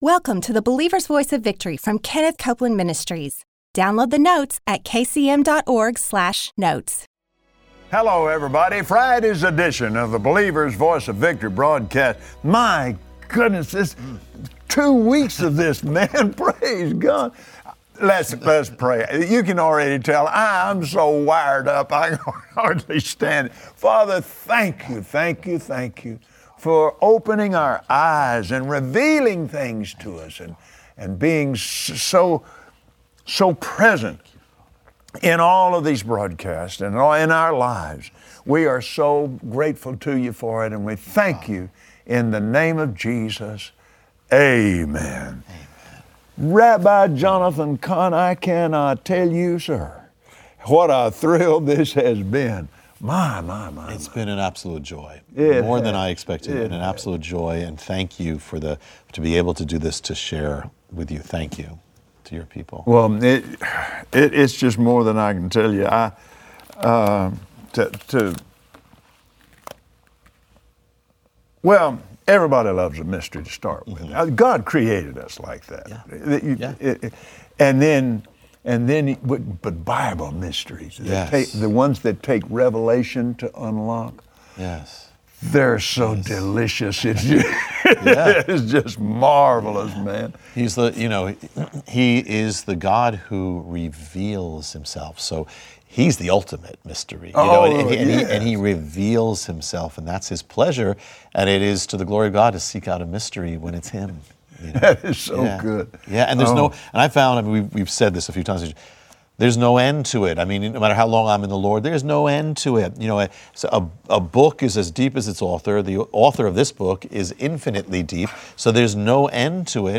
Join Kenneth Copeland and Rabbi Jonathan Cahn today as they explain the mystery of the jubilee. Learn how God has orchestrated all of human existence to show His Glory to all mankind.